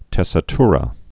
(tĕsĭ-trə)